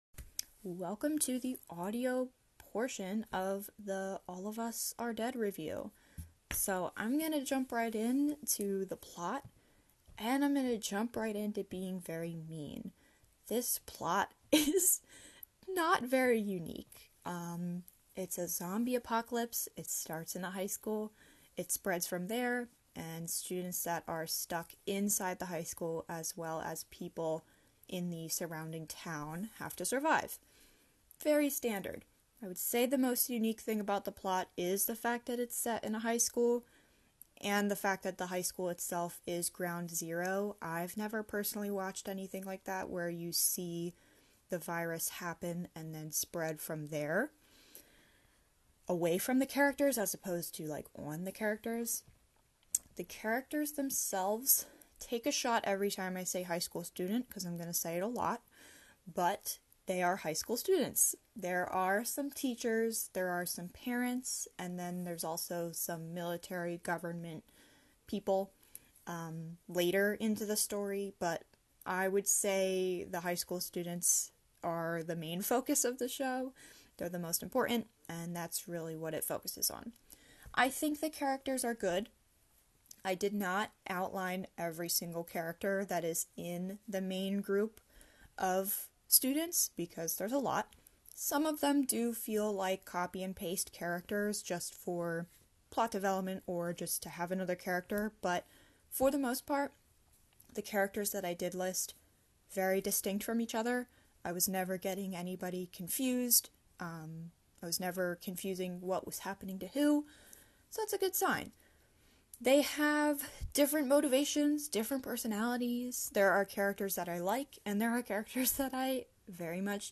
All of Us Are Dead: An audio review